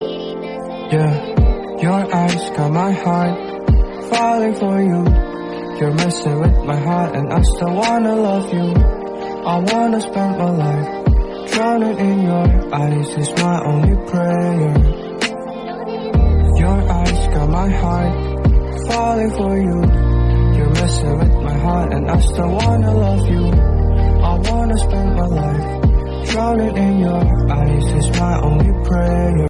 Hindi Ringtones, Ringtones